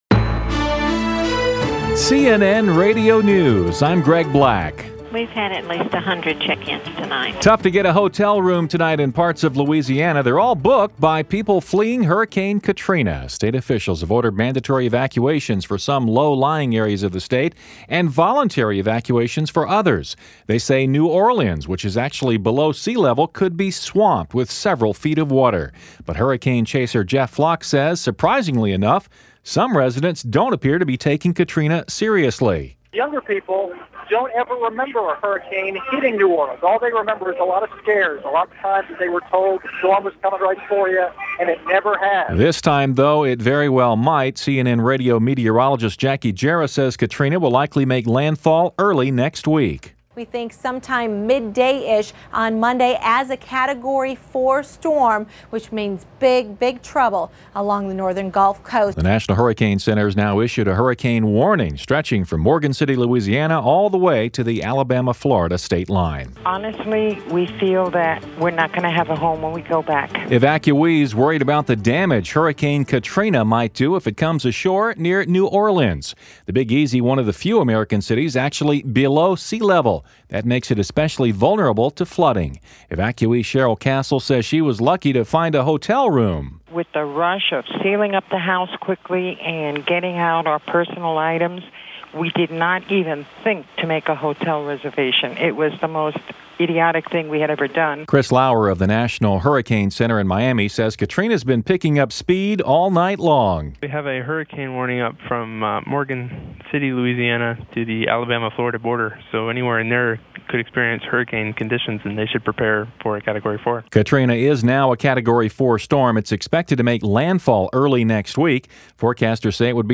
– CNN Radio – Hourly News 1:00 am – 7:00 pm EDT – August 28, 2005 –